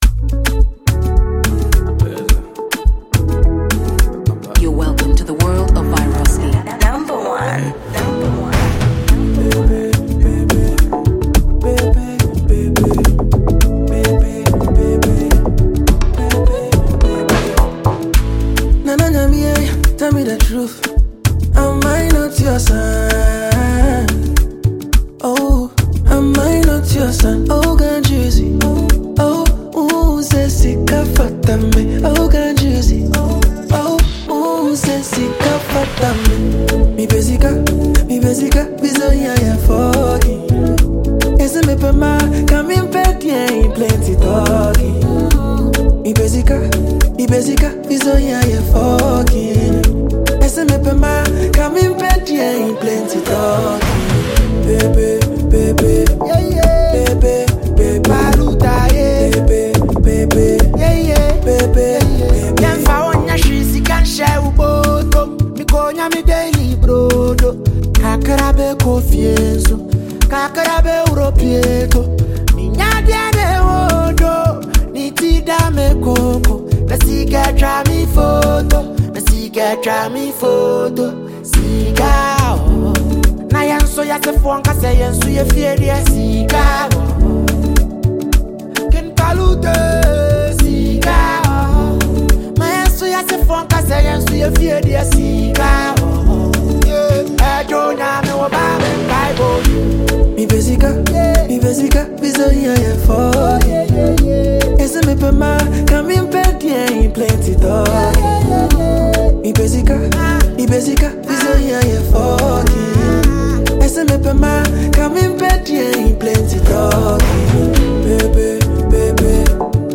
catchy new single